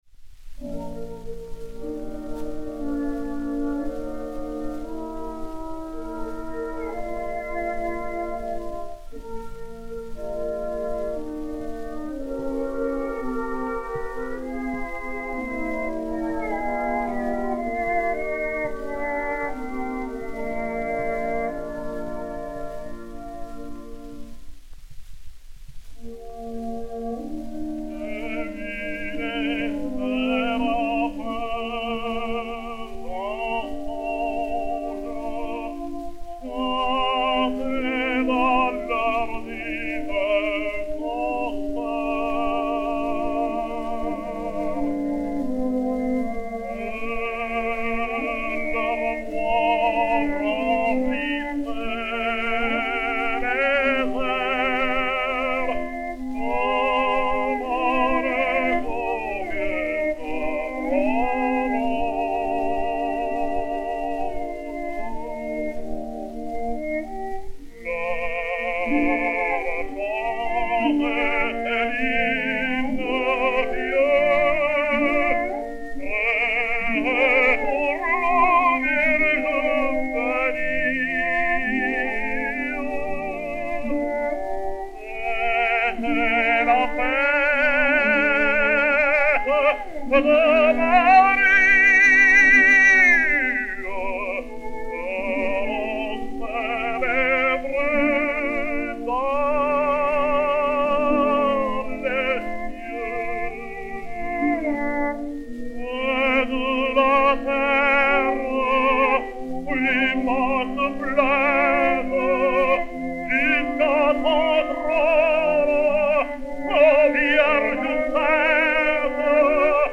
Hymne
violoncelle
enr. à New York le 20 mars 1916